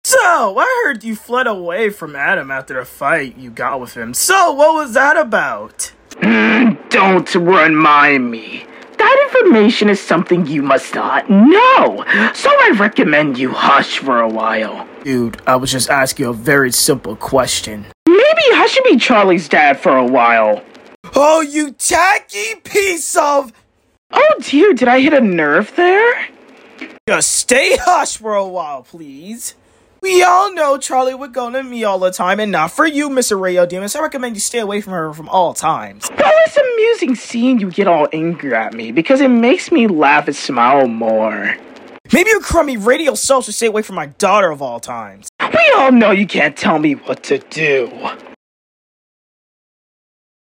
Hazbin Hotel Voiceover Alastor And Sound Effects Free Download